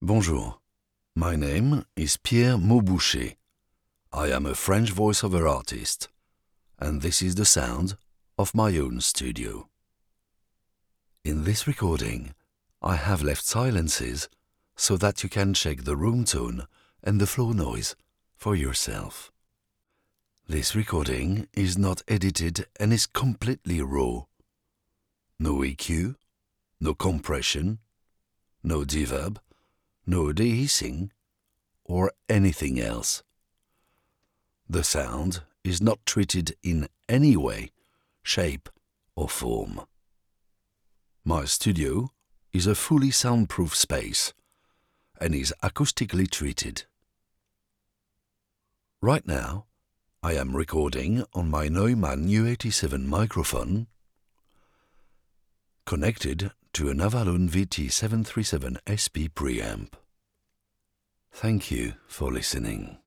Neumann U87 microphones, Sennheiser MKH416, Avalon VT-737SP preamp, SSL2+ soundcard, Pro Tools Studio, iMac, dedicated acoustic treatment >
This is a 1mn audio presentation of my studio demonstrating its sound. It is a 24bit/48kHz WAV file, dry, raw, not cleaned and not treated in any way (no EQ, no compression or anything else)
The sound booth produces a matte and neutral sound without being 'muffled,' with an exceptionally low floor noise of -80 dB RMS.